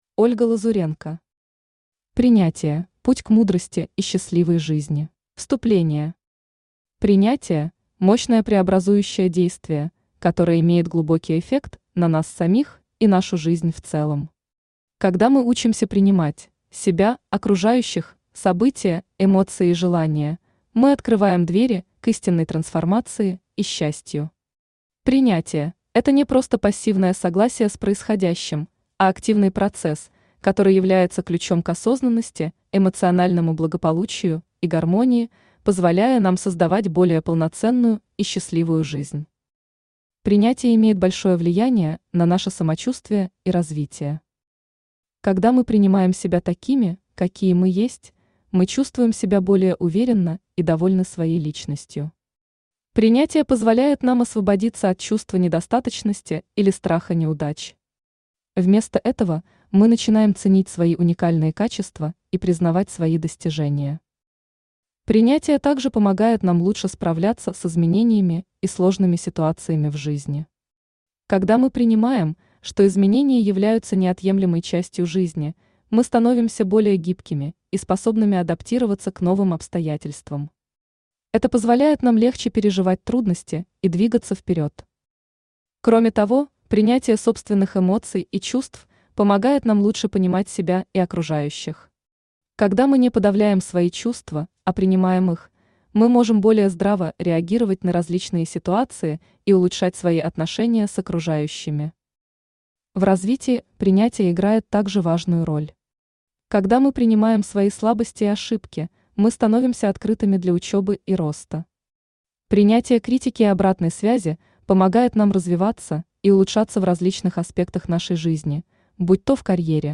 Читает: Авточтец ЛитРес
Аудиокнига «Принятие: путь к мудрости и счастливой жизни».